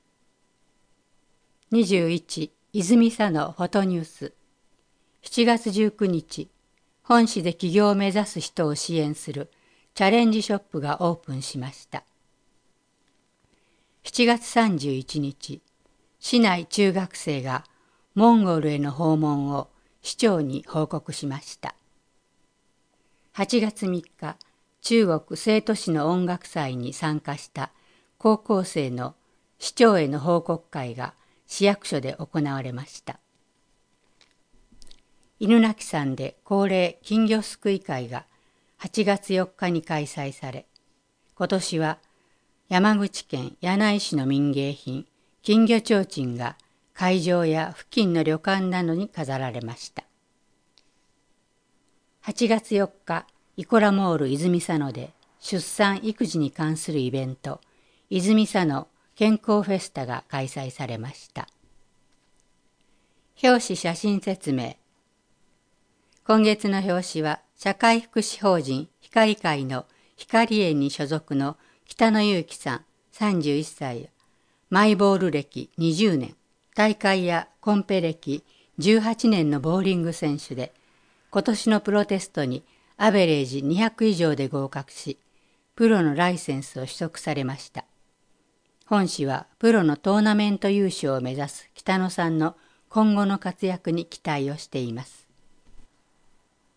このページでは、視覚障害をお持ちの方のために泉佐野市社会福祉協議会「声のボランティア」のみなさんが朗読した広報の音声ファイルをダウンロードできます。